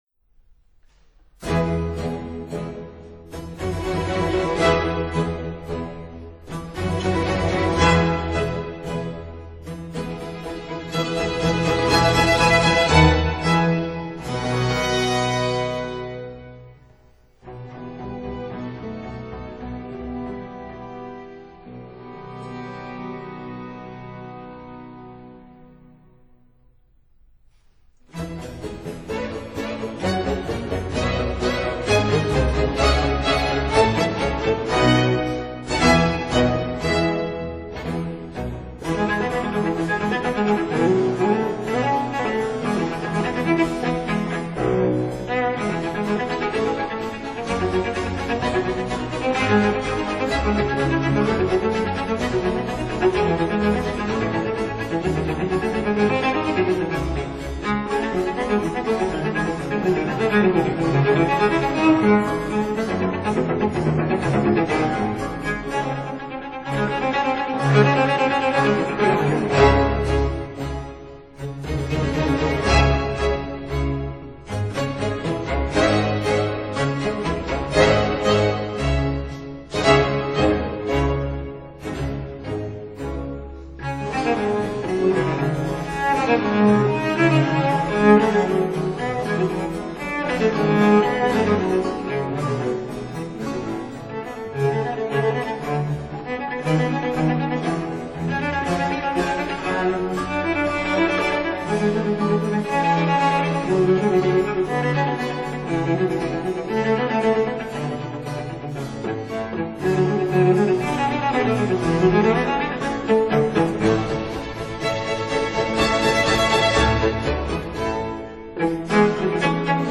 Larghetto    [0:02:26.15]
Allegro    [0:02:23.73]